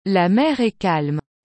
• Dans une phrase déclarative (qui se termine par un simple point), la voix doit descendre à la fin. Exemple : « La mer est calme. »